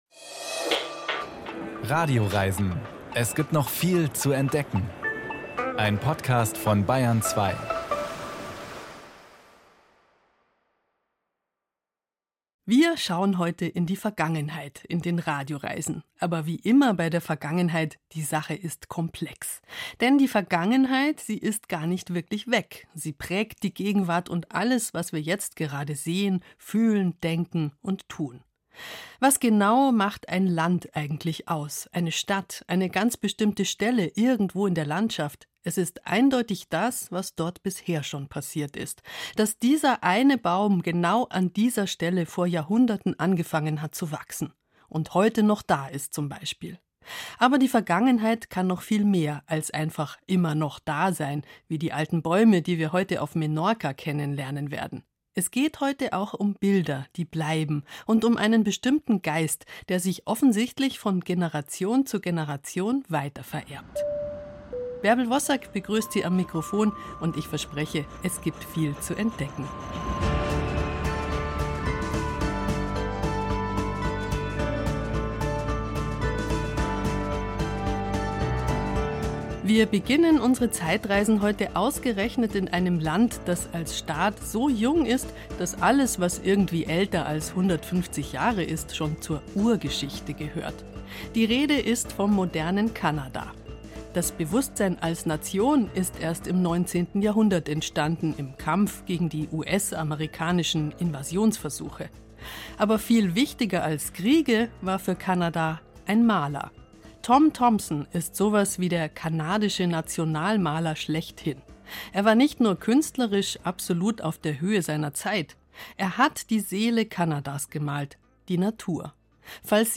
Mit Reportagen, Interviews, Sounds und Musik tauchen wir tief in spannende Welten ein.